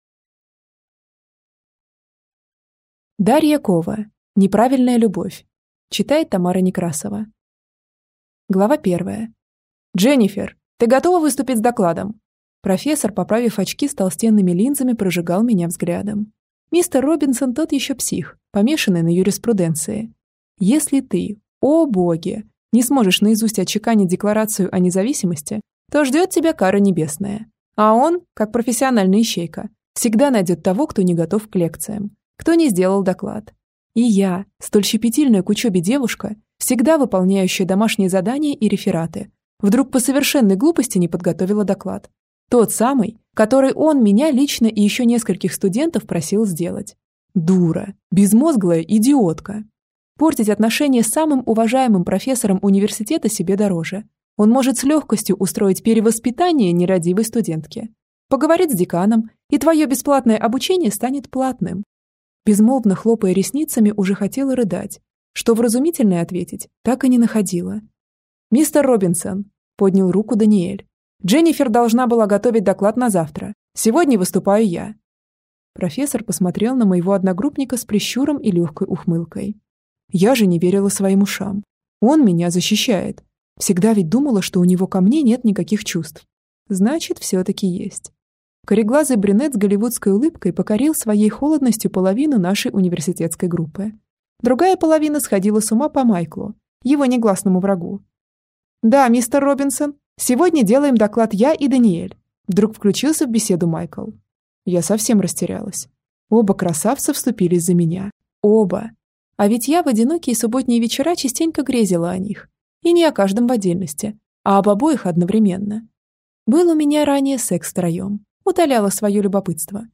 Аудиокнига Неправильная любовь | Библиотека аудиокниг